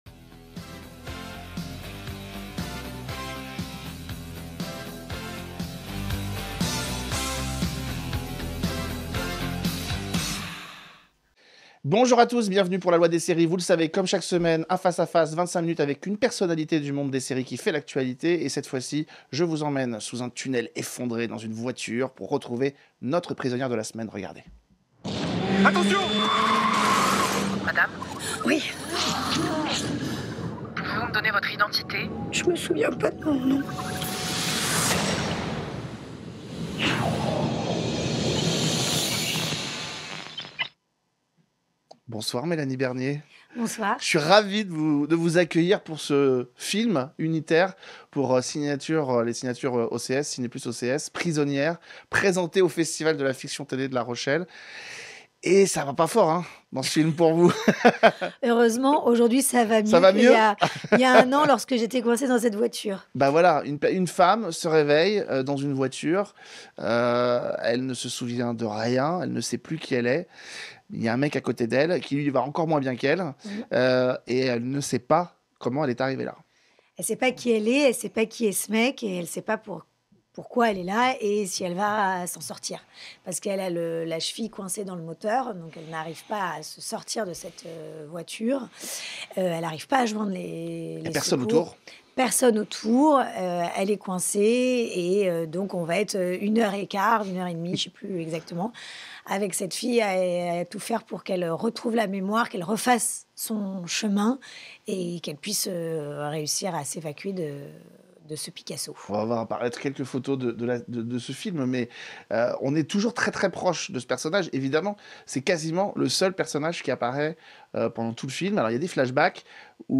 A l'occasion de la diffusion de l'unitaire Prisonnière sur Ciné+ Frisson, nous recevons Mélanie Bernier, héroïne de ce huis clos infernal.
L’invitée : Mélanie Bernier pour Prisonnière